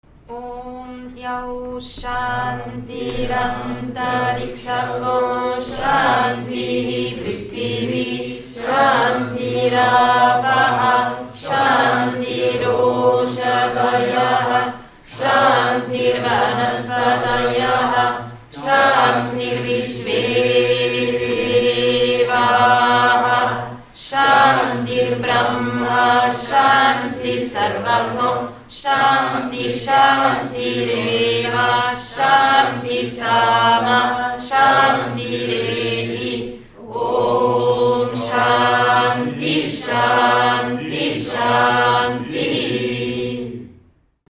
Vedische Rezitationen für den Weltfrieden